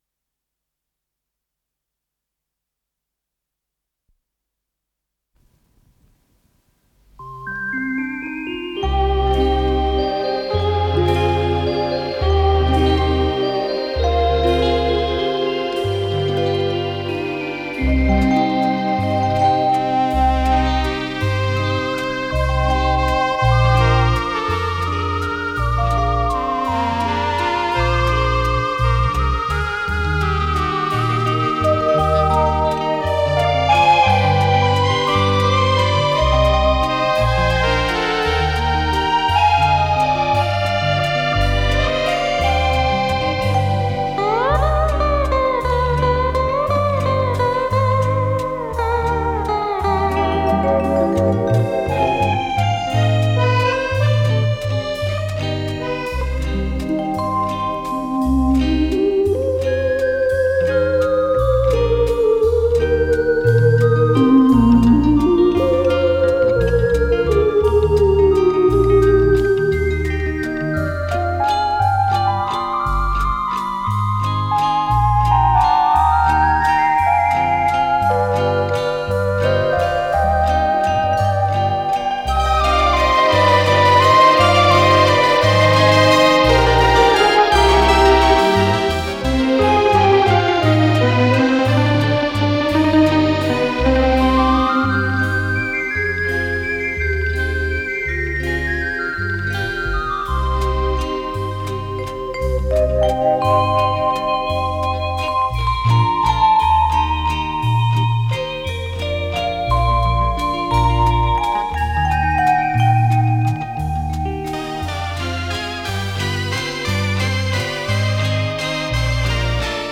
с профессиональной магнитной ленты
инструментальный вариант песни
ВариантДубль моно